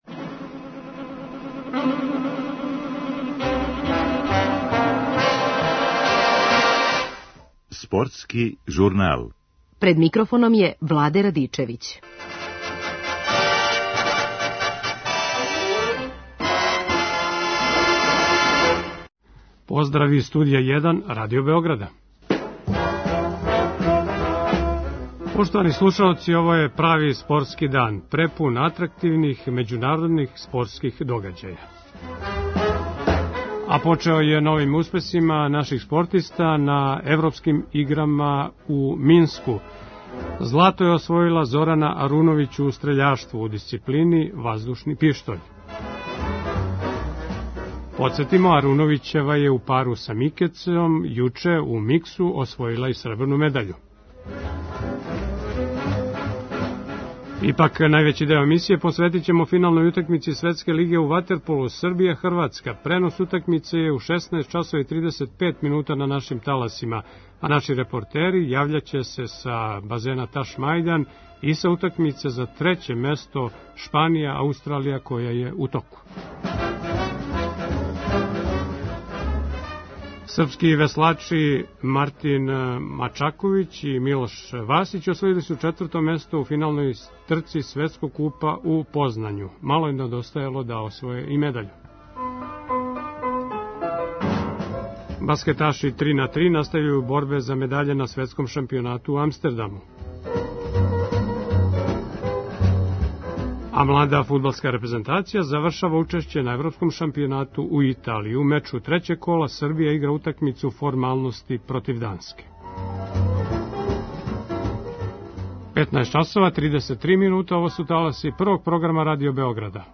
Наши репортери јављаће се са Ташмајдана и са утакмице за треће место Шпанија - Аустралија.